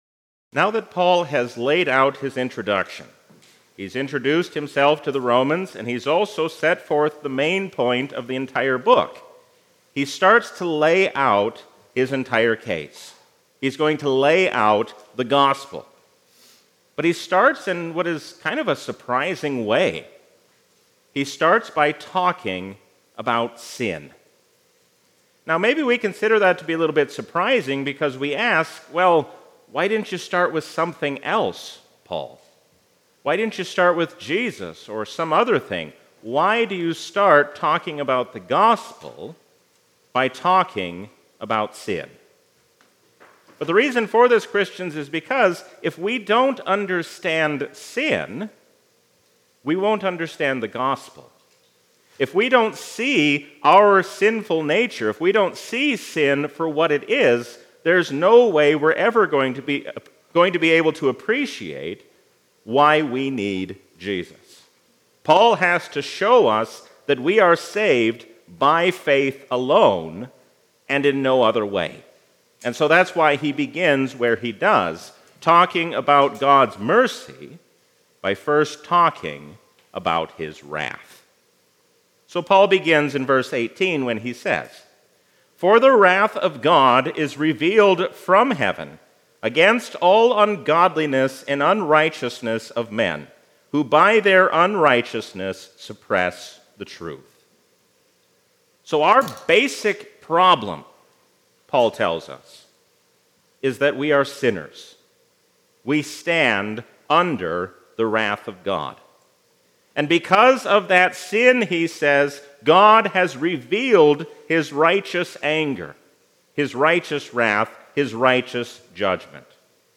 A sermon from the season "Trinity 2024." If God can forgive sinners like King Manasseh, He can also forgive you.